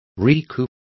Complete with pronunciation of the translation of recouped.